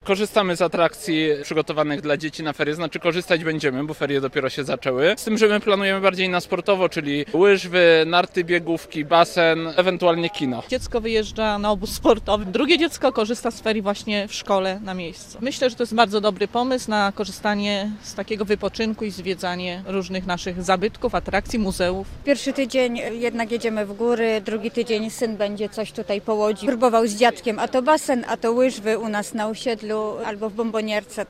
Jak planujemy spędzić ferie? Zapytaliśmy łodzian - Radio Łódź
Zapytaliśmy, czy łodzianie chętnie korzystają z tych atrakcji: Nazwa Plik Autor Ferie w Łodzi audio (m4a) audio (oga) Ferie w województwie łódzkim potrwają do 15. lutego.